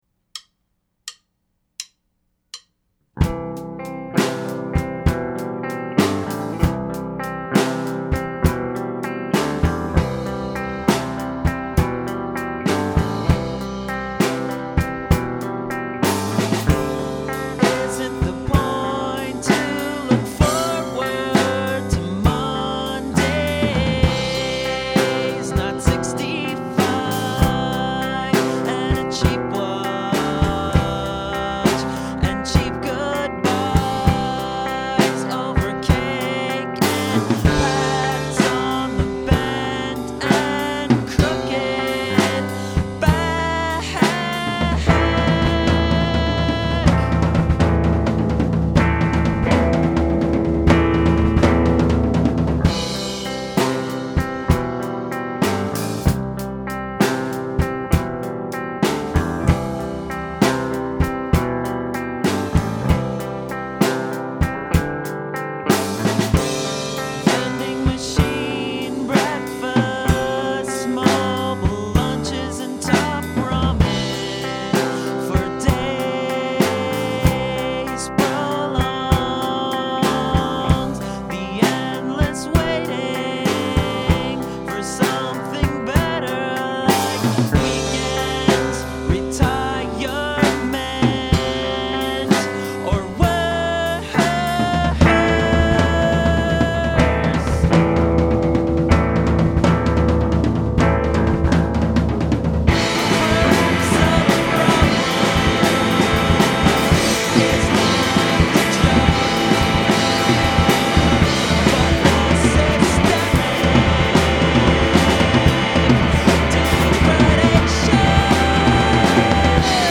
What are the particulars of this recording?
recorded at bartertown studios